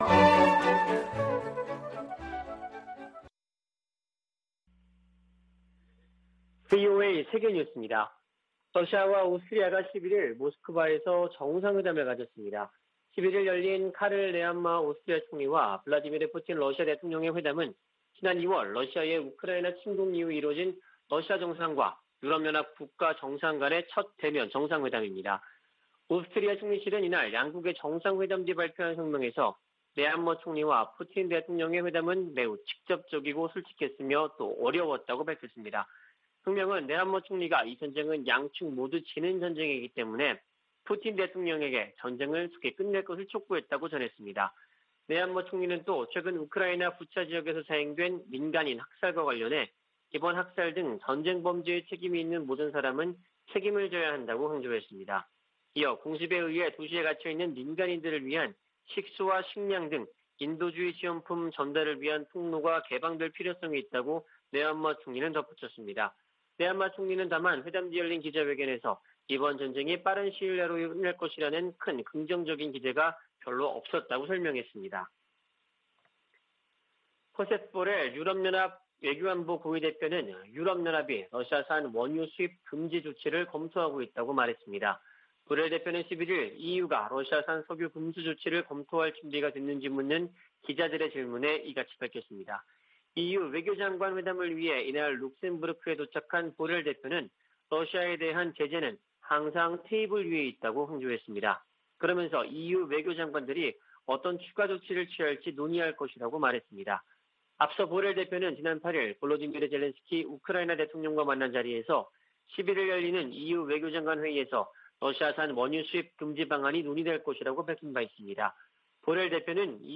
VOA 한국어 아침 뉴스 프로그램 '워싱턴 뉴스 광장' 2022년 4월 12일 방송입니다. 미 국무부는 북한 비핵화 목표에 정책 변화가 없다고 밝히고, 대화에 나오라고 북한에 촉구했습니다. 미 국방부는 북한의 위협을 잘 알고 있다며 동맹인 한국과 훈련과 준비태세를 조정하고 있다고 밝혔습니다. 북한이 추가 핵실험을 감행할 경우 핵탄두 소형화를 위한 실험일 수 있다는 전문가들의 지적이 이어지고 있습니다.